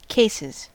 Ääntäminen
Etsitylle sanalle löytyi useampi kirjoitusasu: cases Cases Ääntäminen US Haettu sana löytyi näillä lähdekielillä: englanti Käännöksiä ei löytynyt valitulle kohdekielelle.